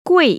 [guì] 꾸이